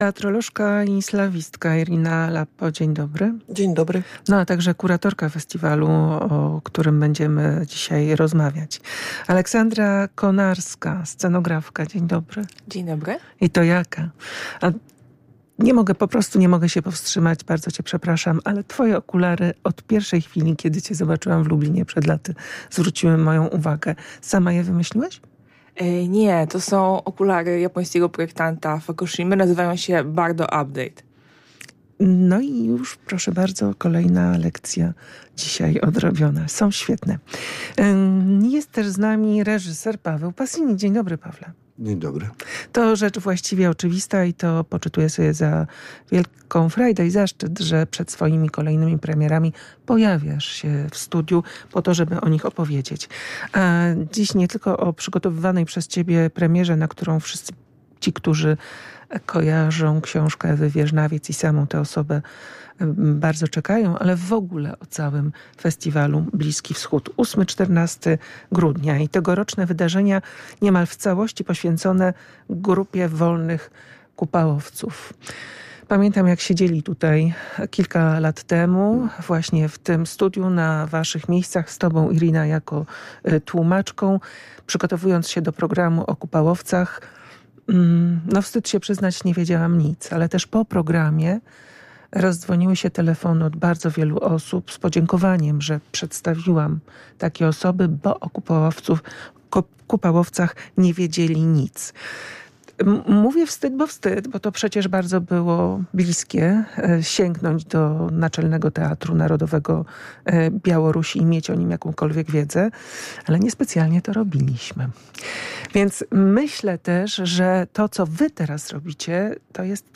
Na kilka dni przed rozpoczęciem wydarzenia o festiwalu opowiadali goście programu